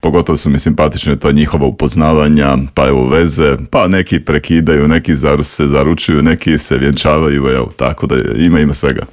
razgovarali smo u Intervjuu Media servisa